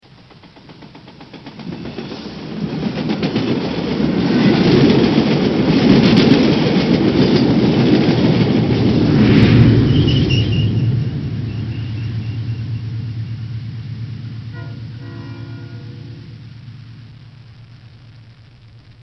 Train sound 3
Category: Sound FX   Right: Personal